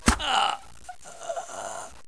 GRUNT7.WAV